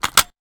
weapon_foley_pickup_02.wav